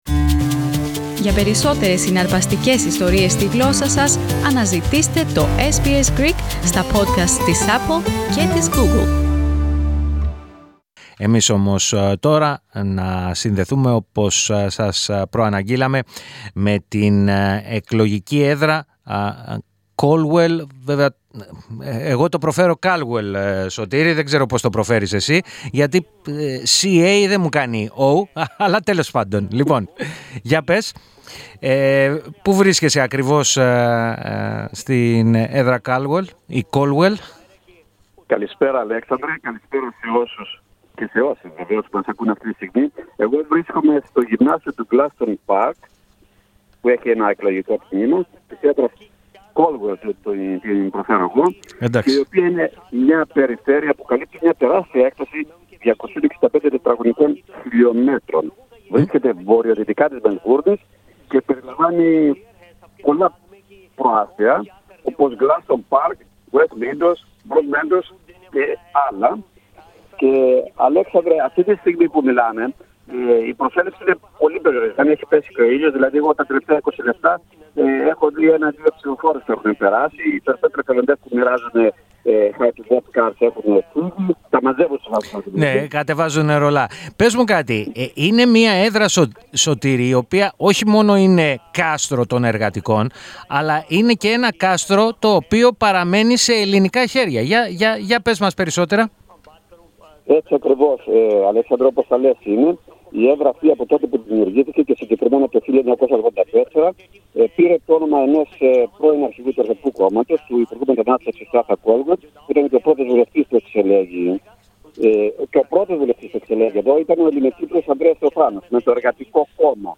The Greek Community in Western Australia honored the memory of the Battle of Crete, along with the passing of 100 years since the Pontic Genocide. We hear more in our weekly correspondence from Perth.